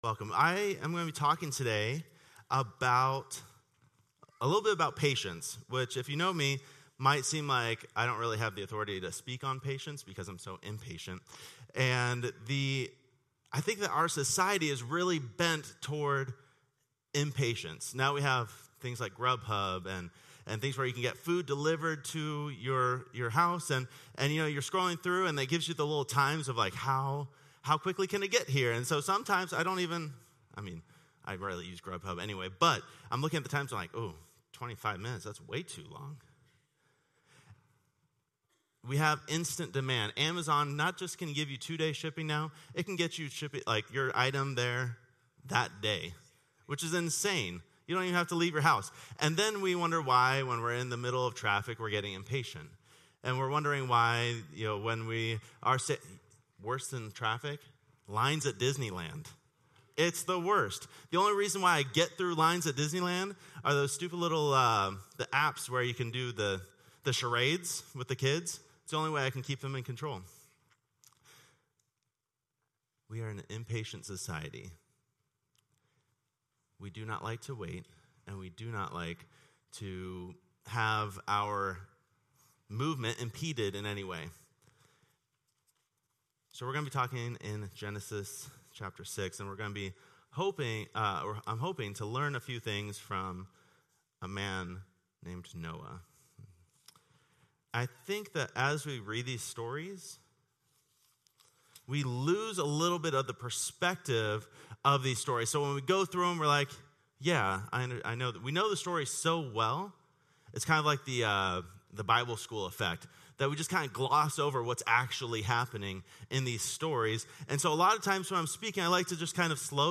Watch this sermon about the timeless wisdom of patience and waiting on the Lord.